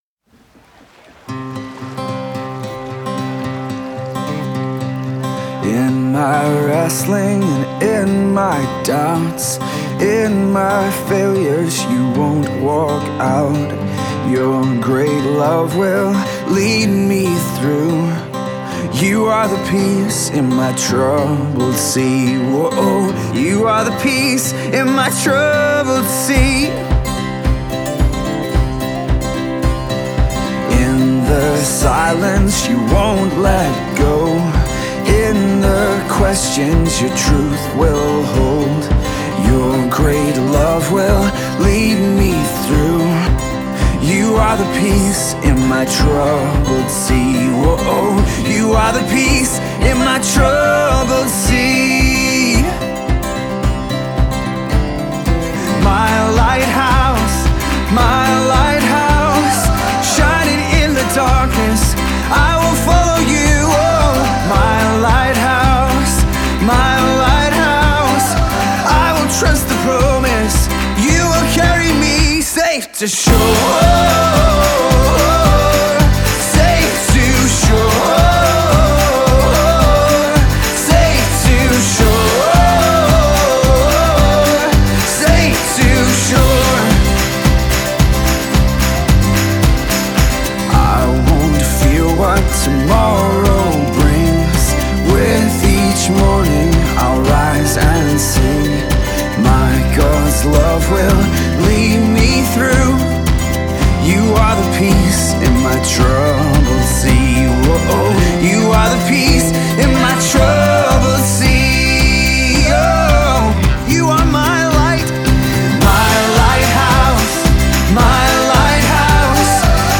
Irish Christian Folk band